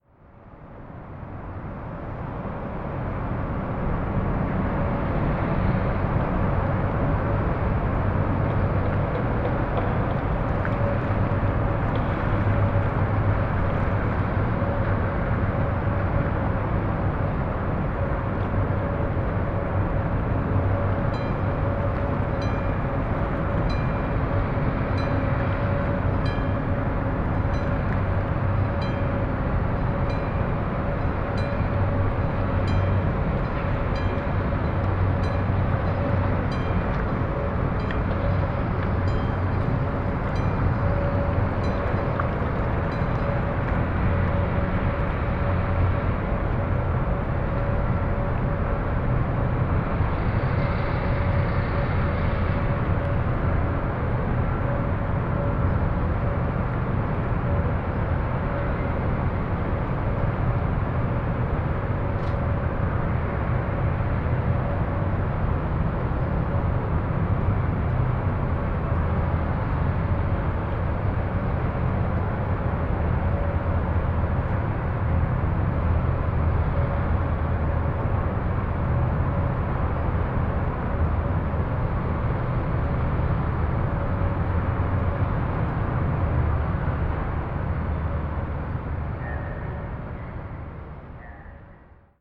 Field Recording Series by Gruenrekorder
Endend nach 589 km in einem industrialen Rauschen, das dem Namen Karlsruhe spottet.